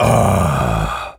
gorilla_growl_deep_01.wav